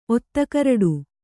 ♪ ottakaraḍu